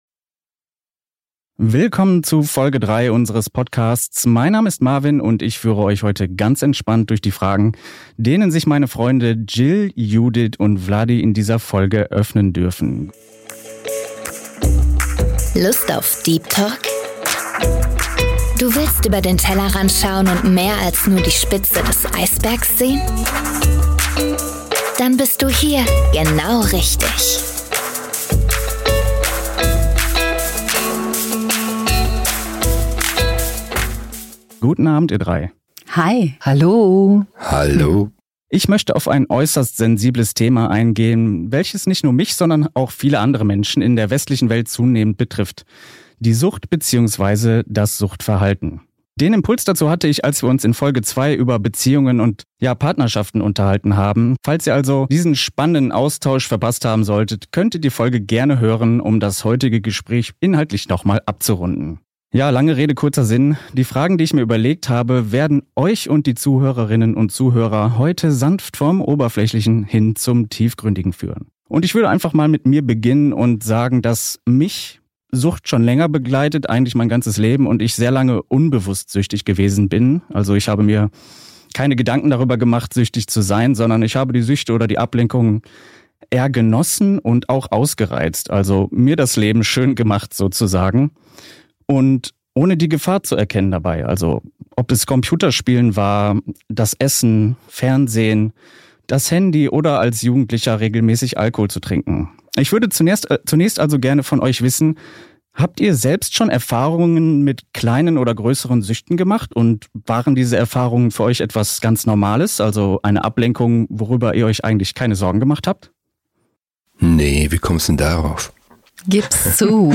Wir reden über Sucht – wie sie beginnt, was sie zerstört, und warum sie oft mit etwas ganz anderem verwechselt wird: Sehnsucht. Ein ehrliches Gespräch über Abhängigkeit, Scham, Rückfälle – und die Kraft, sich selbst nicht aufzugeben.